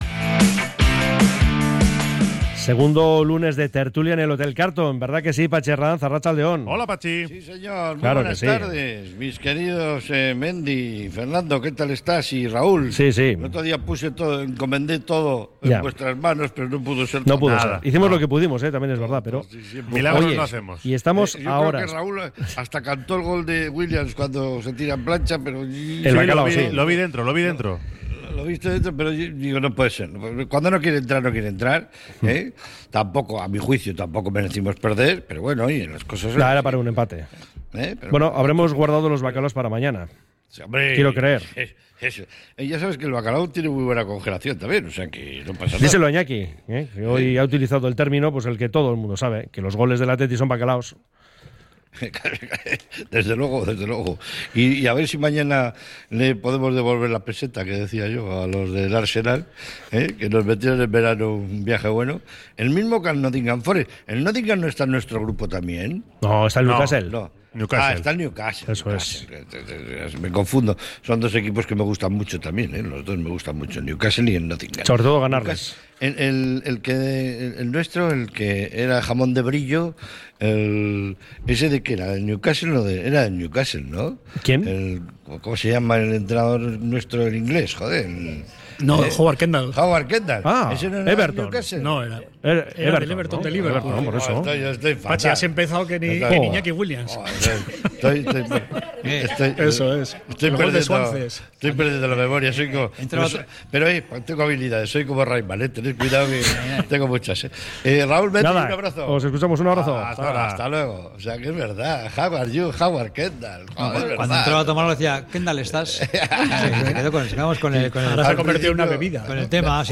desde el Hotel Carlton